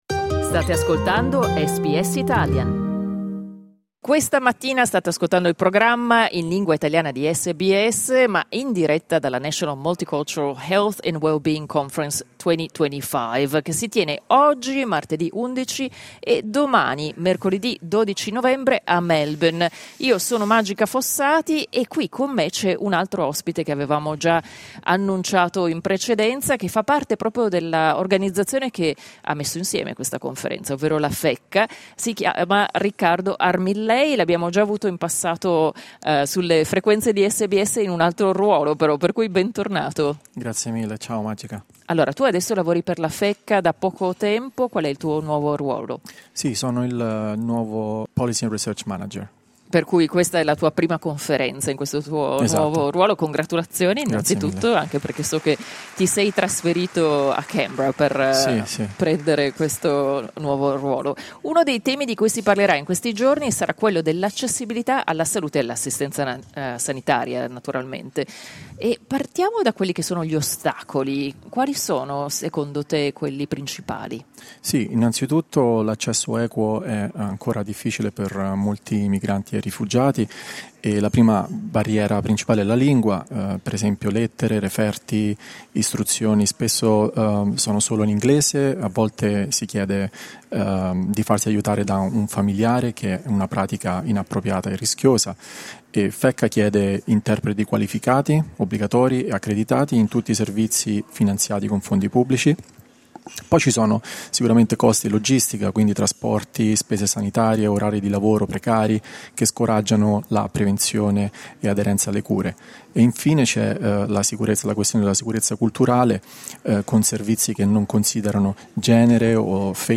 Clicca sul tasto "play" in alto per ascoltare l'intervista integrale